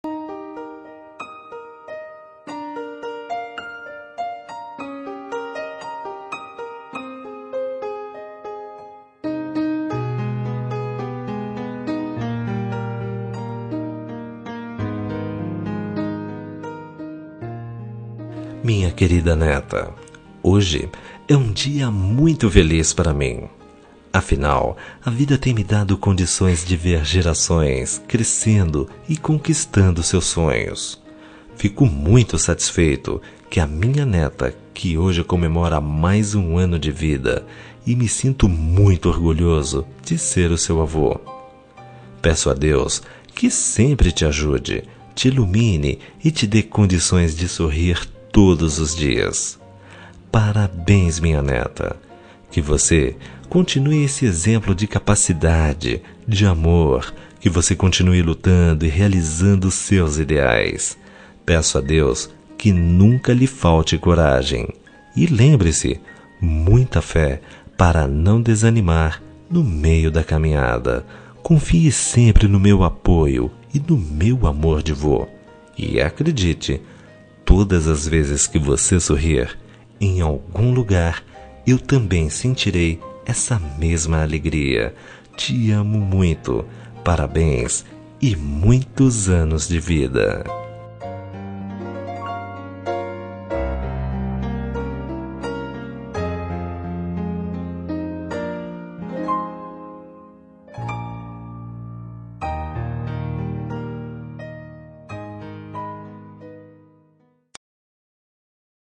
Aniversário de Neta – Voz Masculina – Cód: 131033